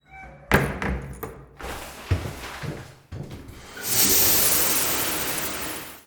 showerStart.ogg